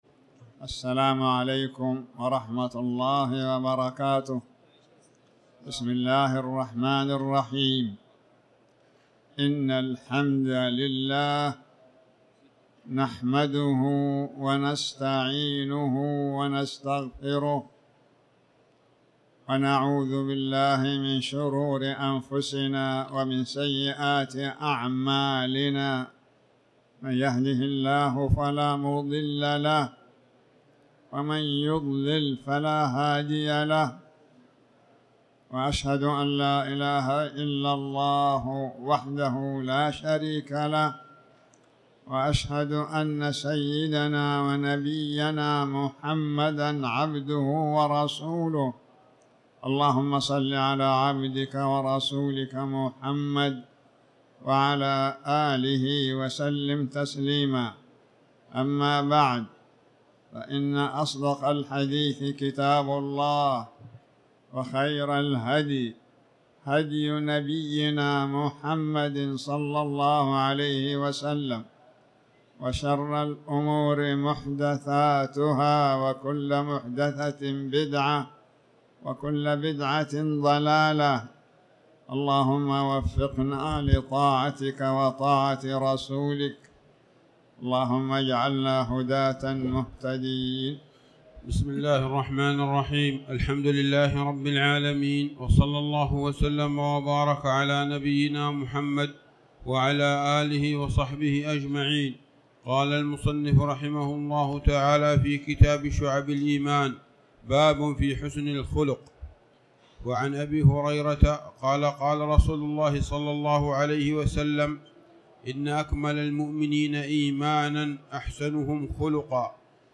تاريخ النشر ٢٦ جمادى الآخرة ١٤٤٠ هـ المكان: المسجد الحرام الشيخ